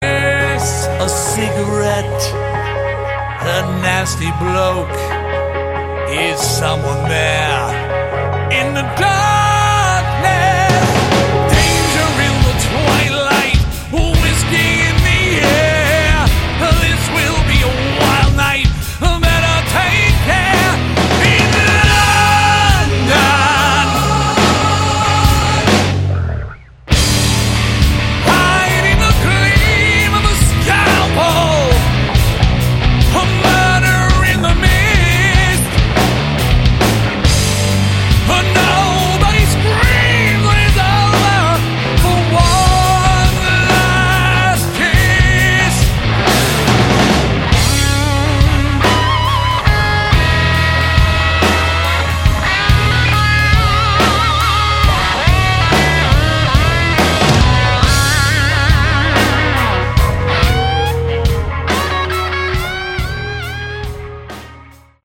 all guitars
lead vocals
bass
keyboards
drums